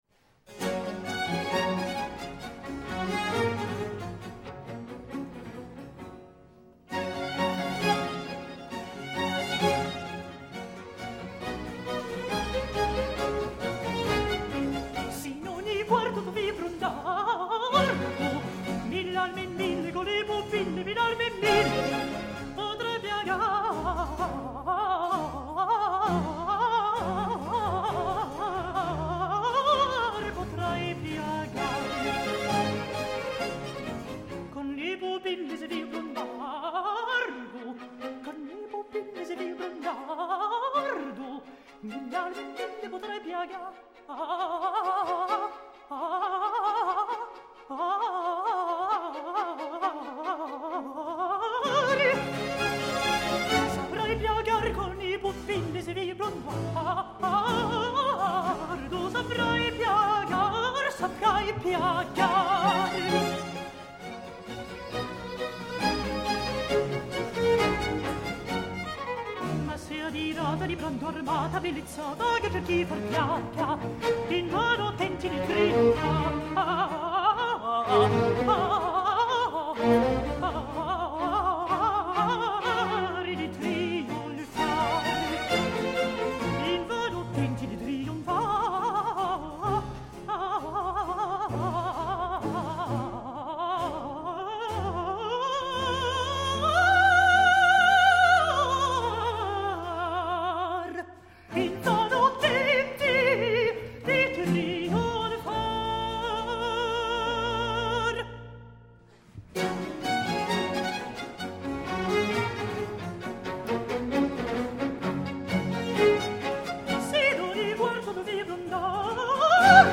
音樂類型：CD古典聲樂